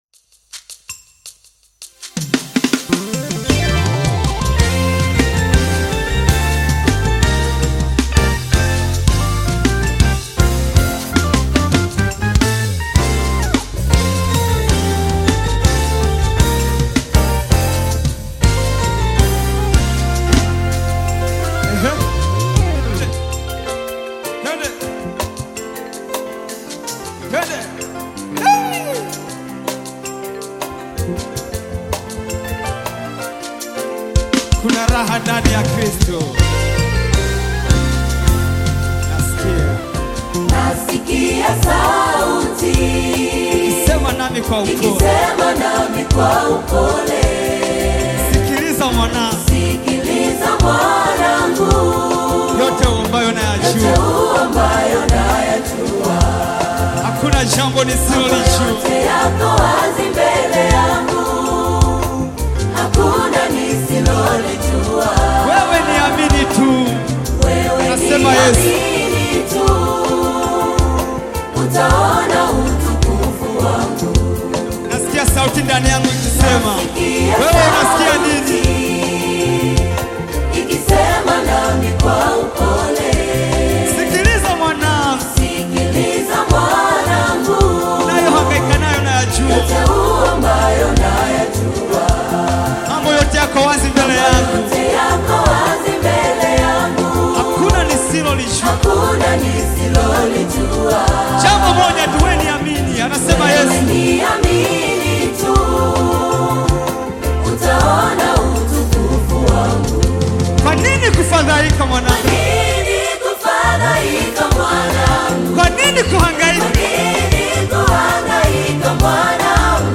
Nyimbo za Dini music
Gospel music track
Tanzanian Gospel artist, singer, and songwriter